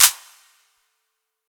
Claps
TS - CLAP (7).wav